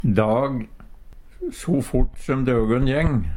dag - Numedalsmål (en-US)
Hør på dette ordet Ordklasse: Substantiv hankjønn Attende til søk